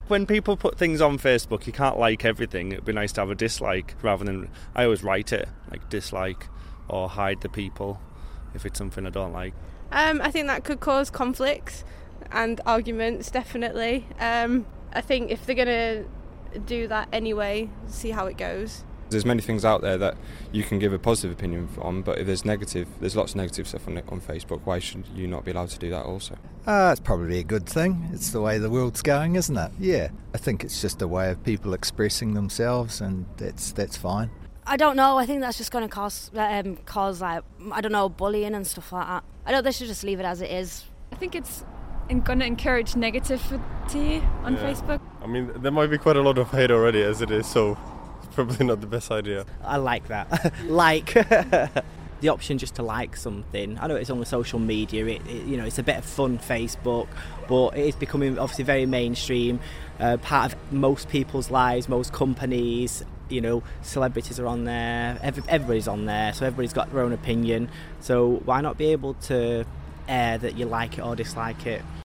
Facebook voxes